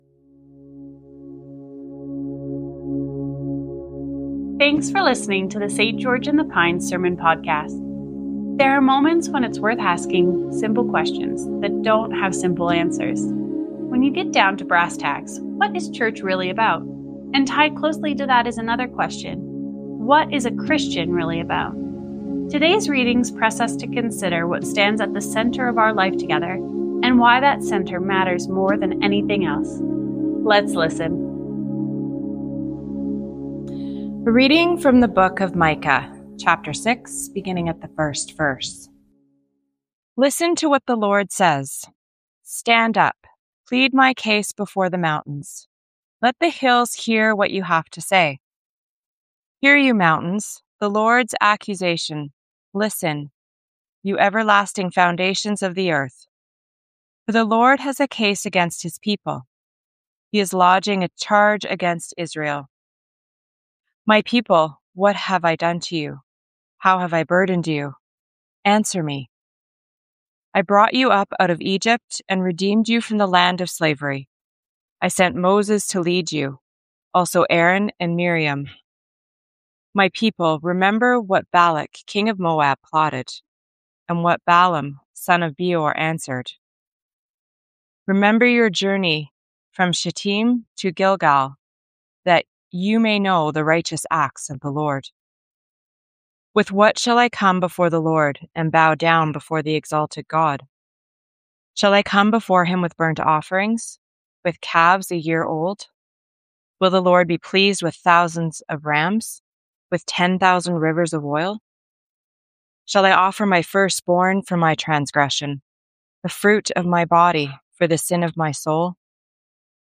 Sermons | St. George in the Pines Anglican Church